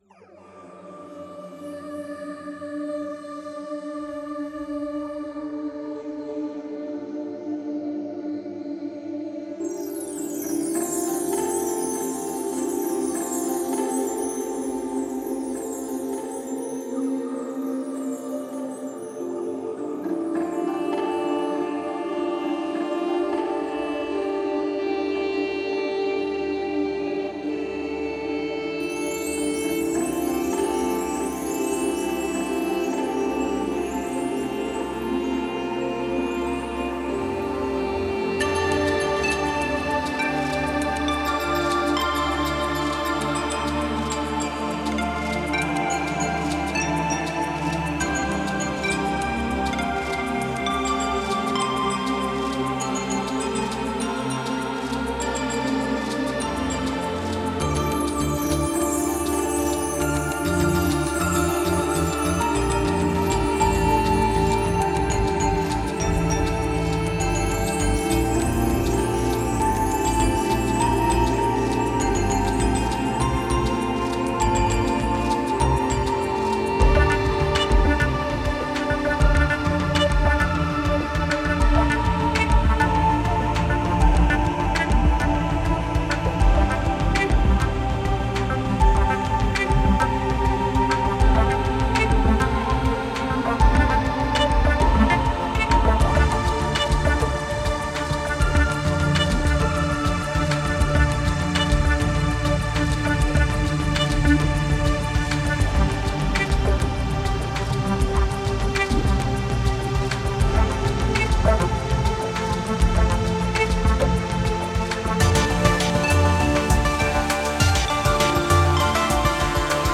Genre: IDM, New Age.